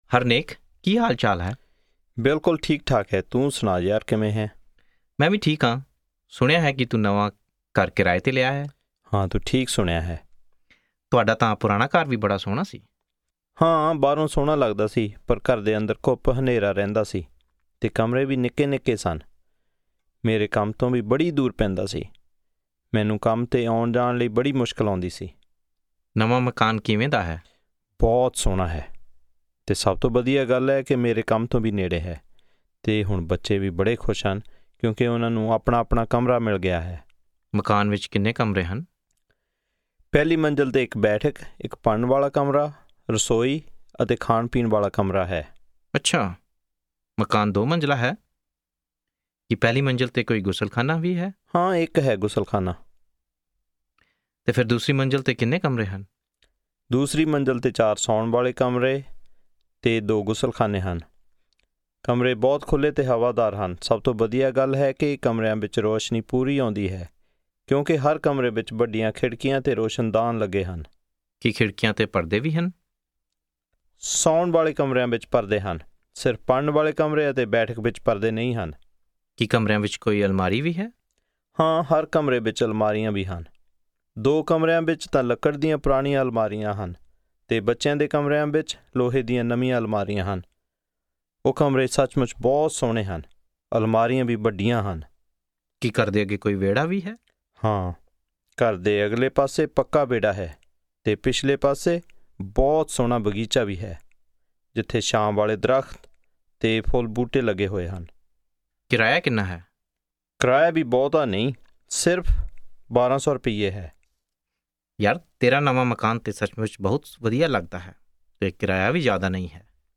Punjabi Conversation 3 Listen